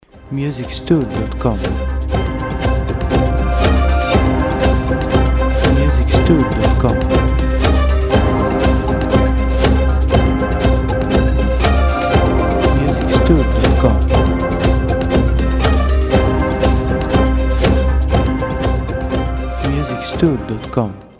• Type : Instrumental
• Bpm : Allegretto
• Genre : New Age / Action / Battle Soundtrack